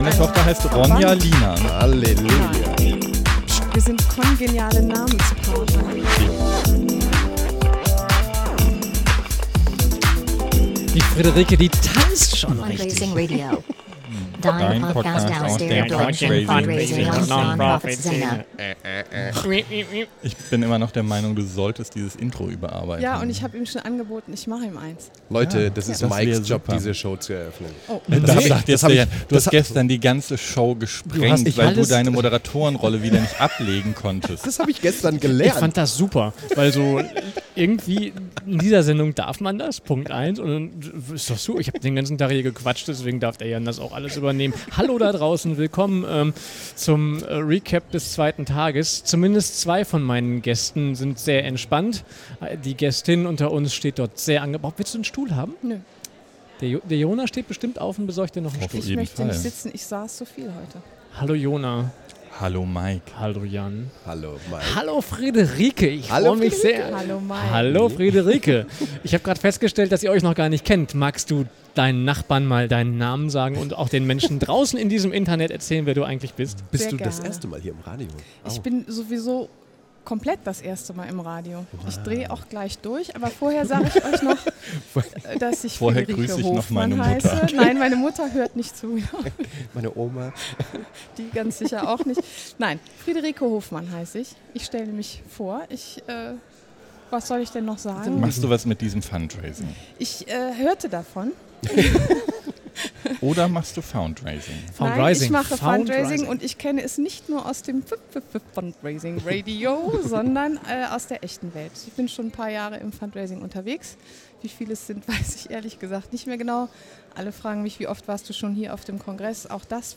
Live von der Ausstellungsfläche des Deutschen Fundraising Kongresses 2017 in Kassel.